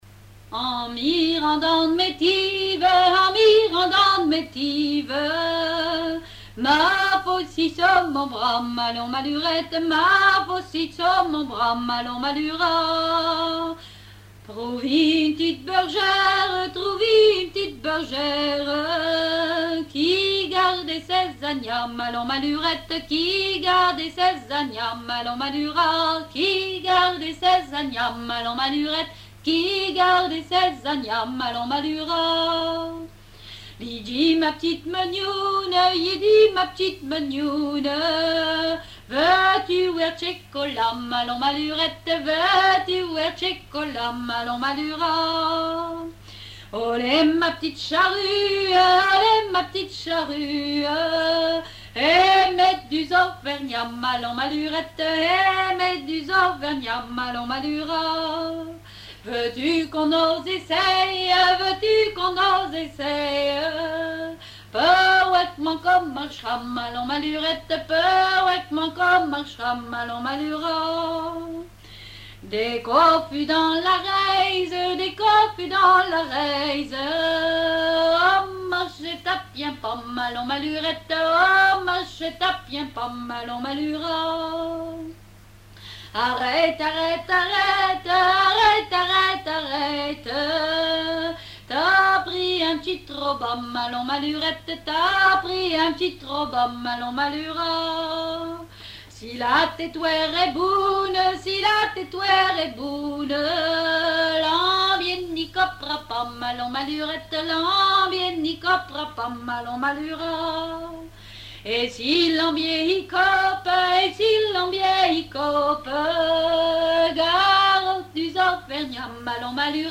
Roche-sur-Yon (La)
Genre laisse
répertoire de chansons traditionnelles
Pièce musicale inédite